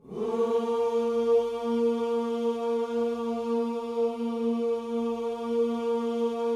WHOO A#3A.wav